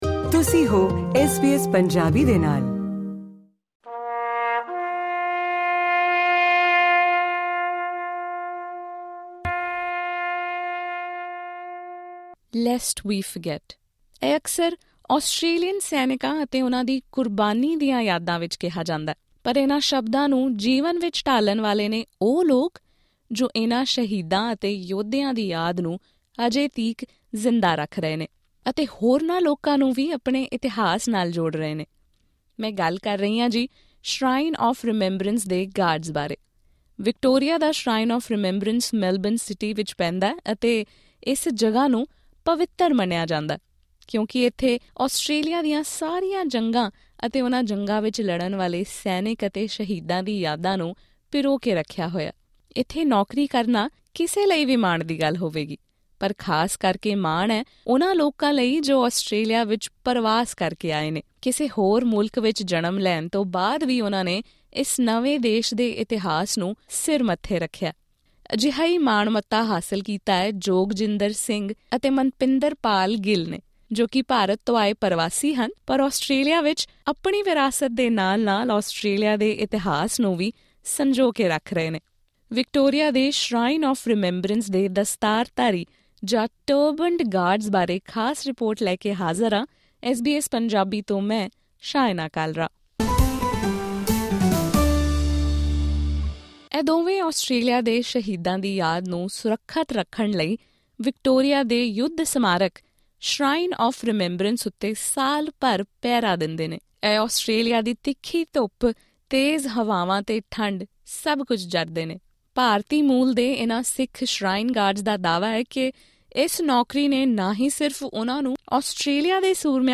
ਇਨ੍ਹਾਂ ਦੋਵੇਂ ਸੁਰੱਖਿਆ ਅਫਸਰਾਂ ਨੇ ਐਸ ਬੀ ਐਸ ਪੰਜਾਬੀ ਨਾਲ ਇੱਕ ਵਿਸ਼ੇਸ਼ ਗੱਲਬਾਤ ਦੌਰਾਨ ਸਾਂਝਾ ਕੀਤਾ ਕਿ ਕਿਵ਼ੇਂ ਇੰਨ੍ਹਾ ਸੇਵਾਵਾਂ ਨੇ ਉਨ੍ਹਾਂ ਨੂੰ ਆਸਟ੍ਰੇਲੀਆ ਦੇ ਜੰਗੀ ਇਤਿਹਾਸ ਬਾਰੇ ਜਾਣੂ ਕਰਵਾਉਣ ਦੇ ਨਾਲ-ਨਾਲ ਖ਼ੁਦ ਦੇ ਪਿਛੋਕੜ ਨਾਲ ਜੁੜਨ ਦਾ ਮੌਕਾ ਵੀ ਦਿੱਤਾ ਹੈ।